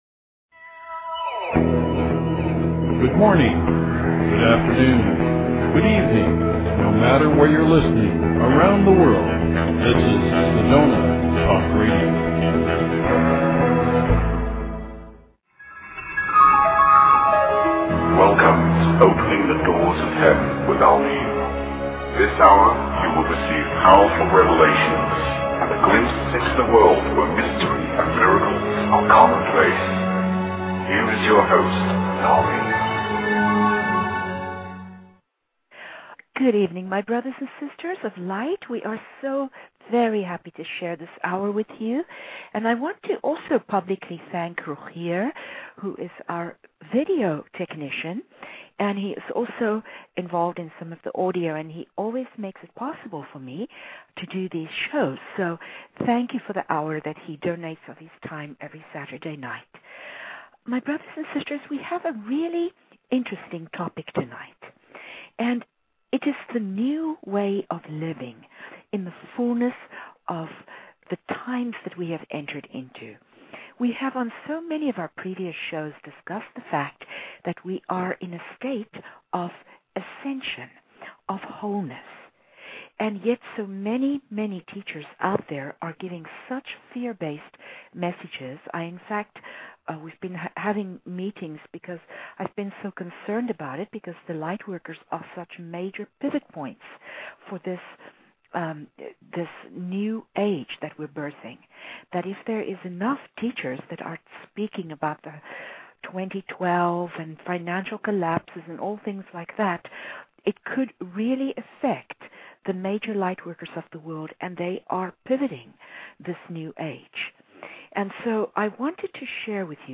Talk Show Episode, Audio Podcast, Opening_the_Doors_of_Heaven and Courtesy of BBS Radio on , show guests , about , categorized as